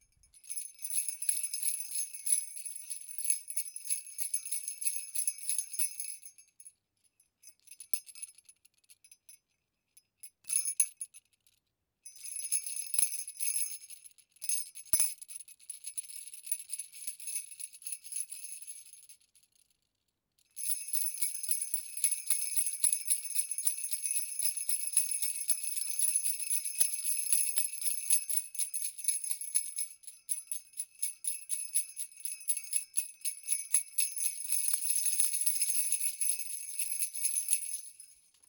jingle-bells.wav